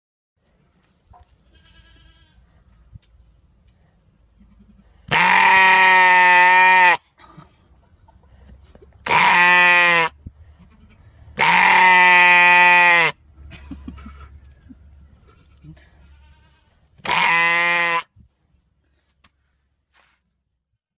Screaming Sheep BAAAAAHH!!!!! Sound Effects Free Download